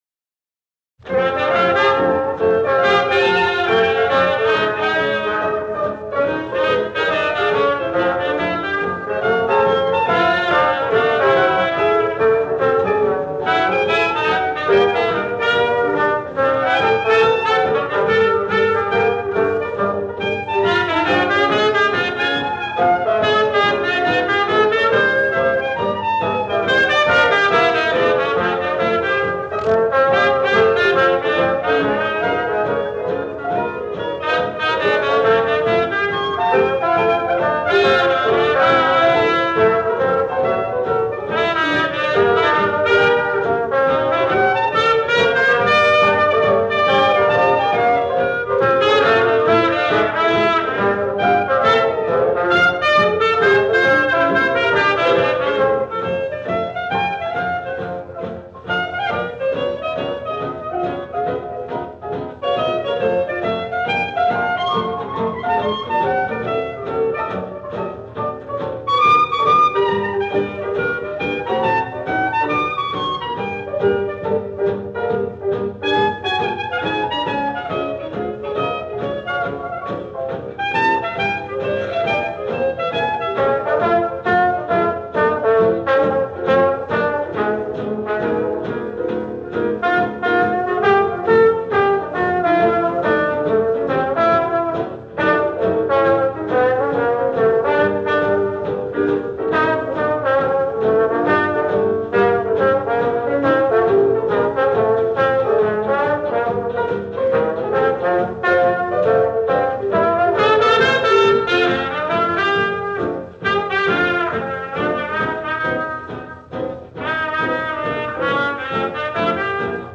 The last testament of a great New Orleans Jazzman